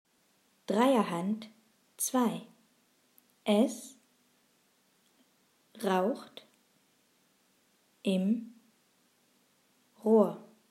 Satz 1 Langsam